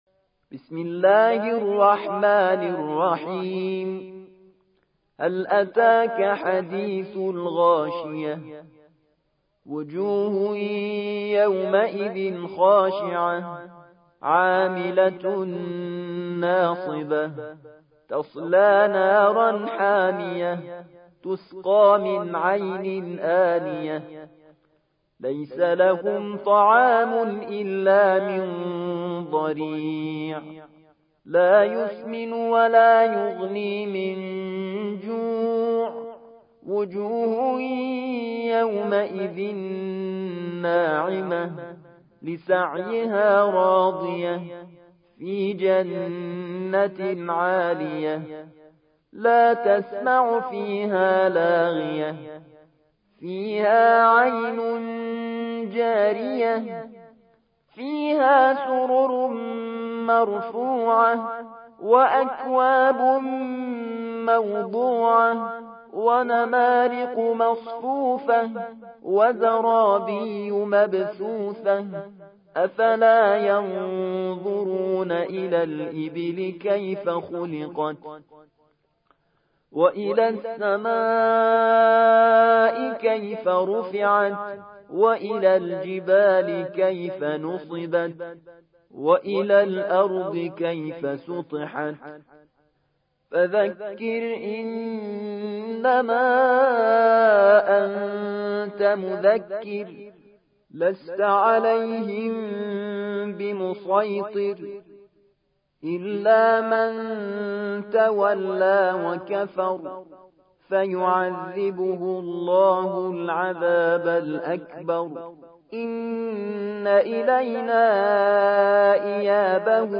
88. سورة الغاشية / القارئ